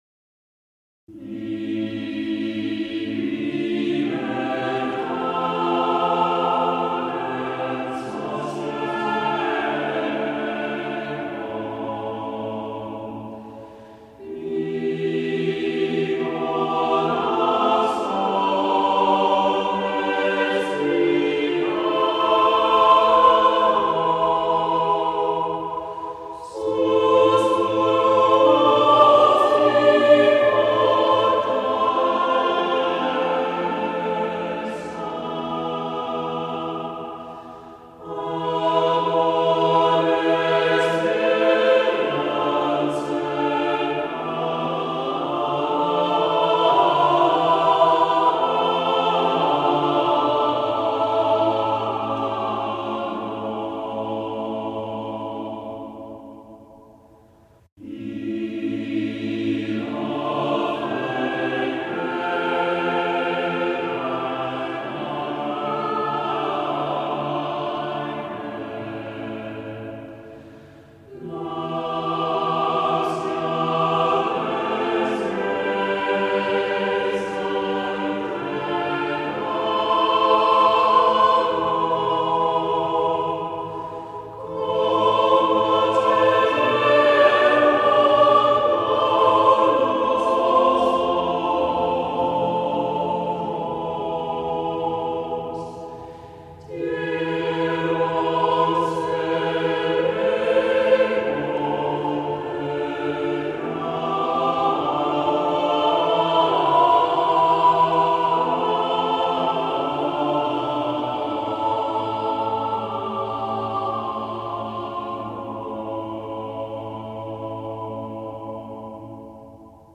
| Vocal ensemble 'Court & Cath.' session 1997